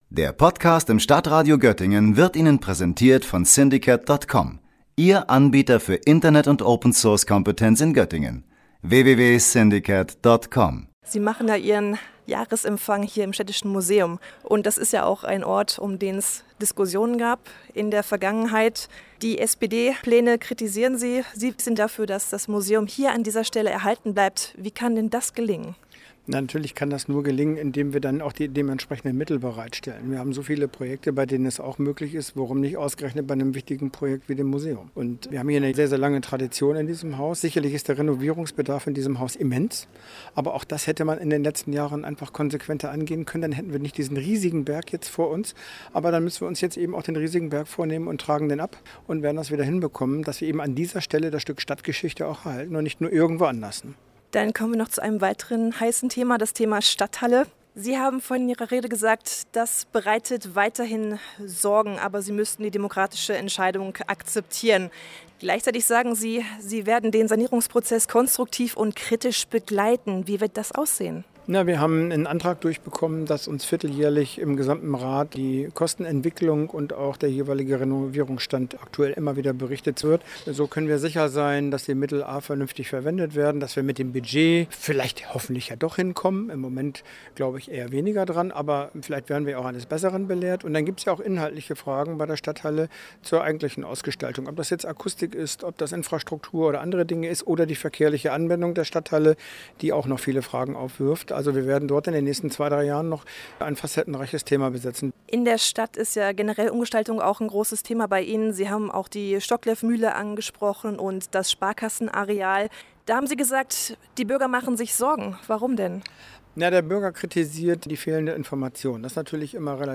Für ihren Jahresempfang hat die Göttinger CDU-Ratsfraktion in diesem Jahr einen besonderen Ort ausgewählt – das Städtische Museum.